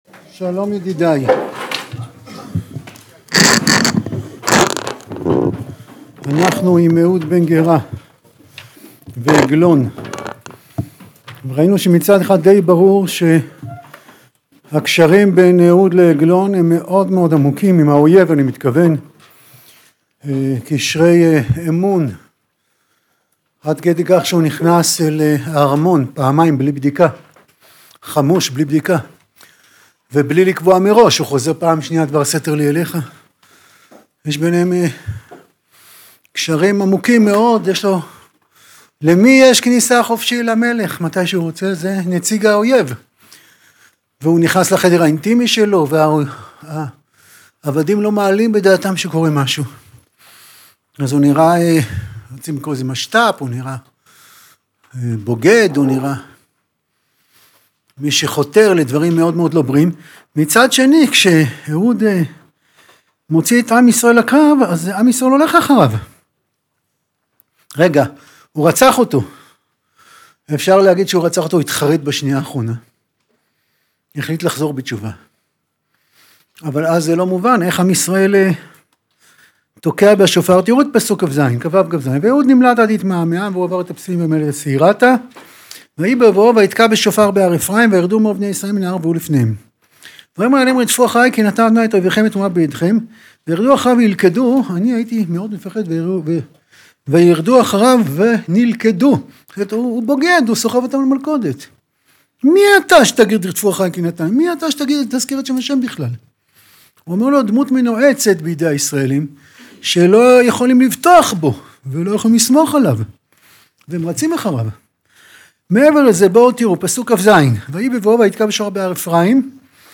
שיעור-תנך-8-MP3.mp3